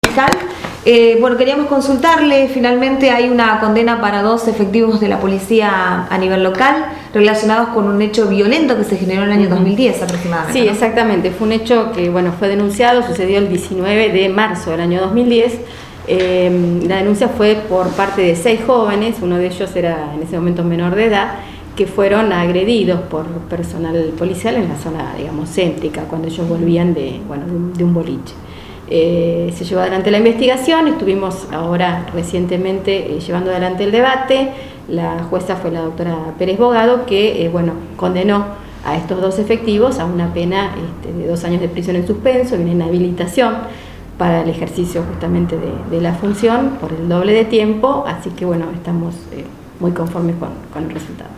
Audios fiscal Marcela Pérez: